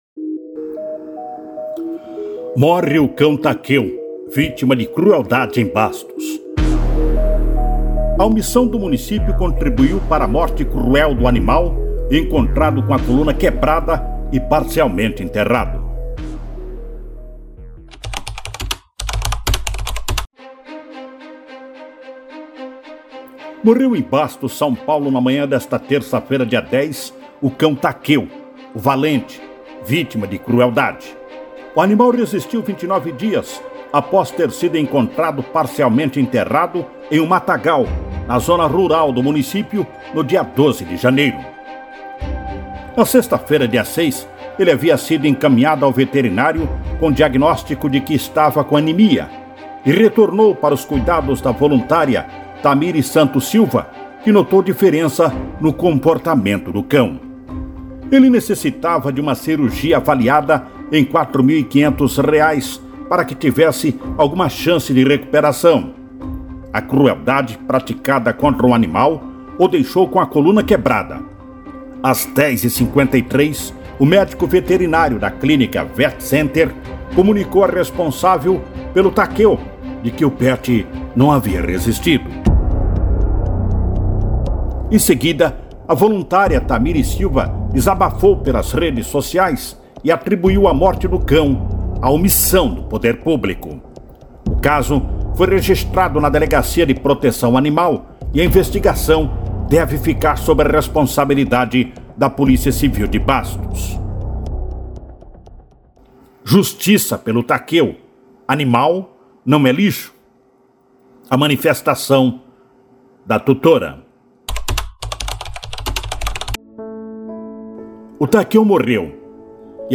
A omissão do município contribuiu para a morte cruel do animal encontrado com a coluna quebrada e parcialmente enterrado – ouça matéria